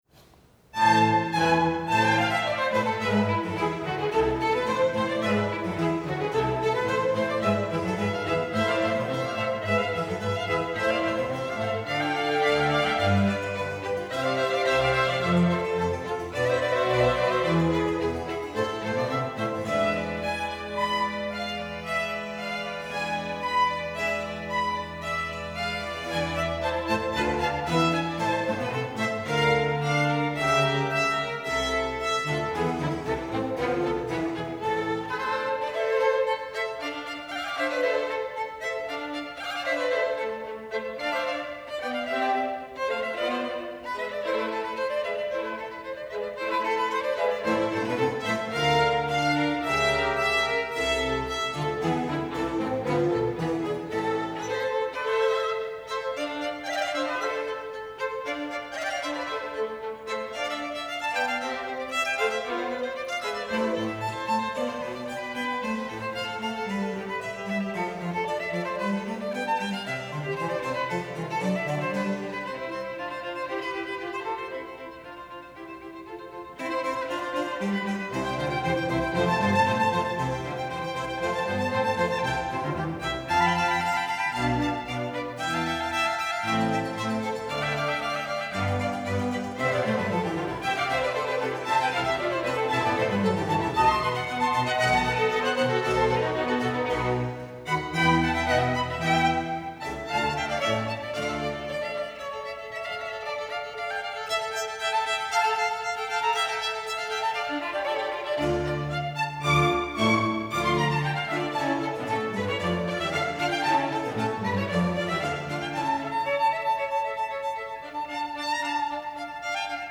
Index of /mp3/chamber_orchestra/MAY-08/
Vivaldi_2violin_concerto.mp3